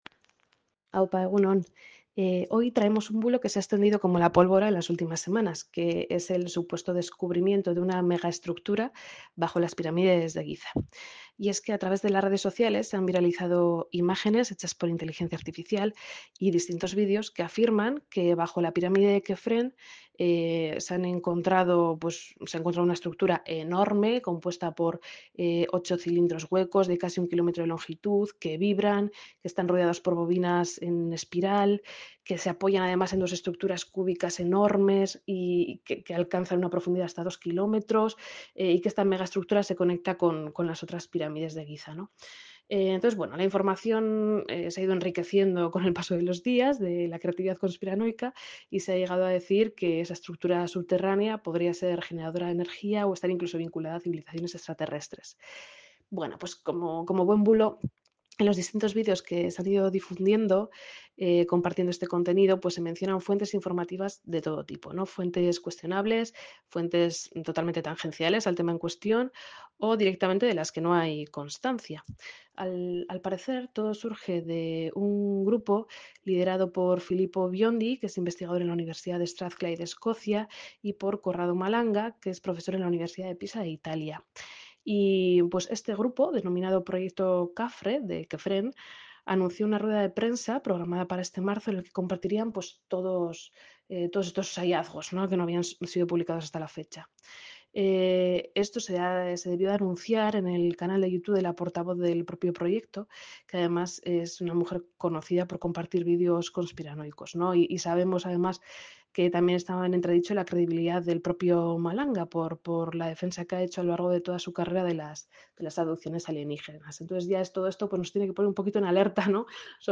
Logos Elkartea desmonta en Radio Popular el bulo de la megaestructura bajo las pirámides de Giza